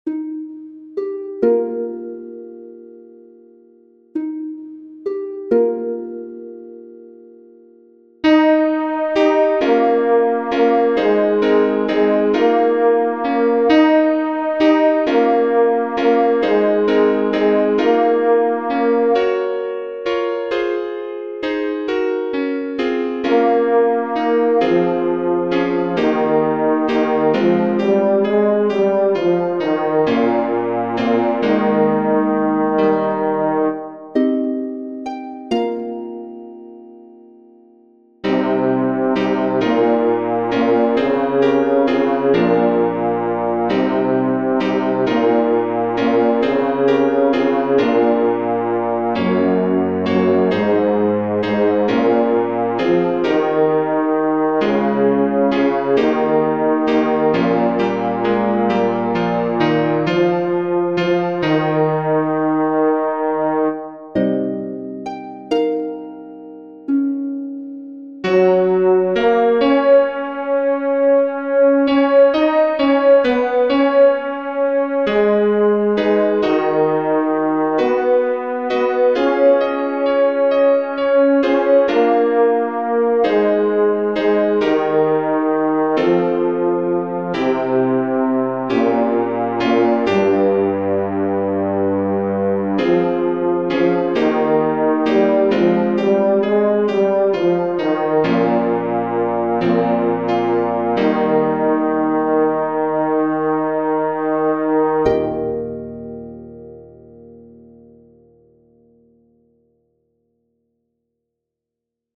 Bass
the_call-bass.mp3